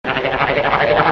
Trombone Gobble
trombone-gobble.mp3